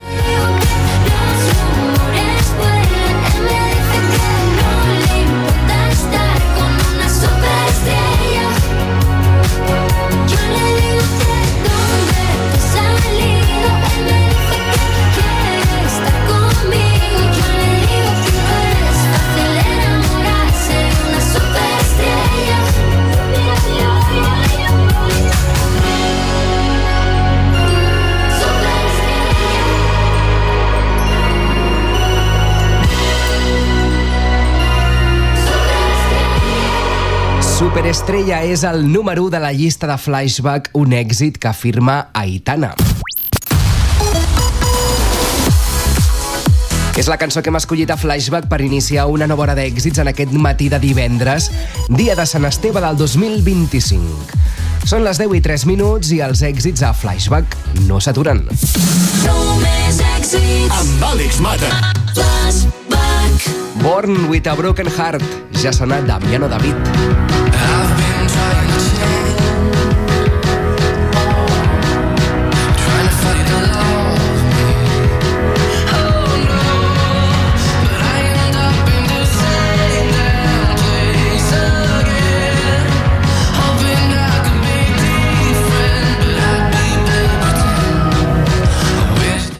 Tema musical, data, identificació i tema musical
Musical